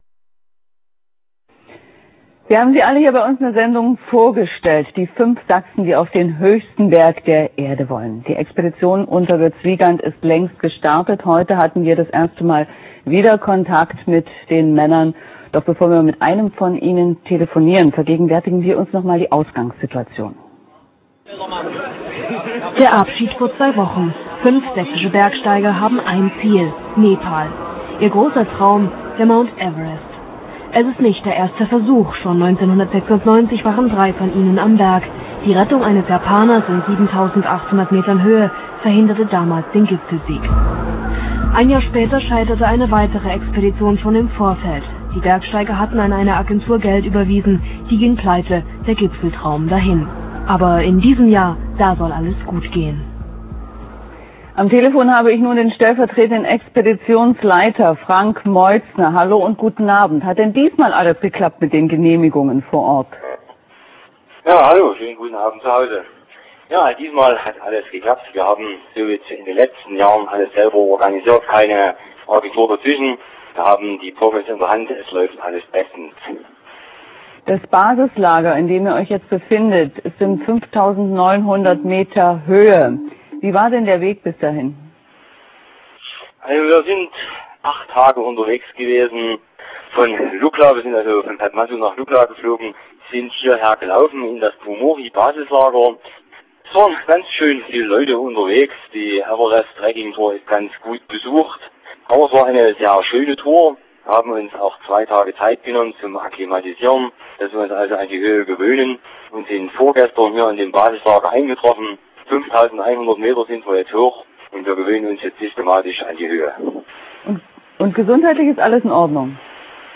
Erstes Telefonat via Satellitentelefon ins